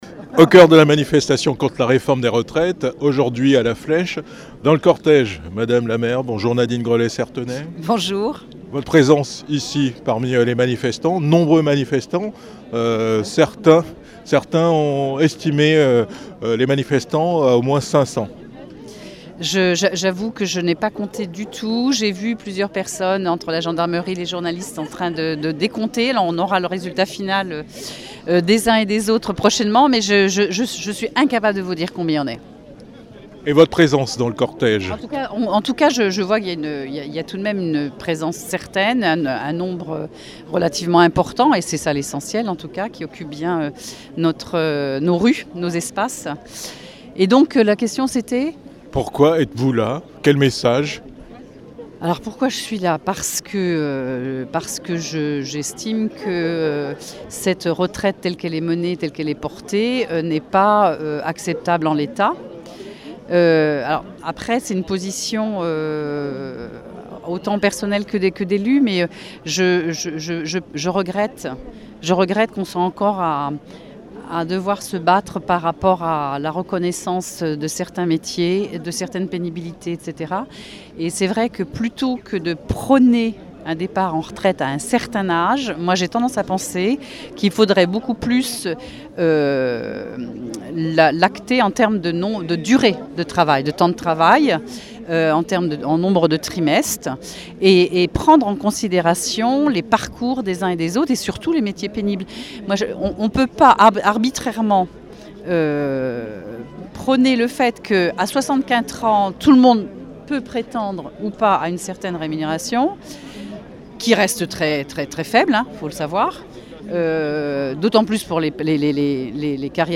Manifestation contre la réforme des retraites à La Flèche
Mobilisation contre la réforme des retraites à La Flèche le 31 janvier 2023. A l'appel d'une intersyndicale plusieurs centaines de personnes se sont rassemblées sur la Place Henri IV après avoir défilé dans les rues de la ville.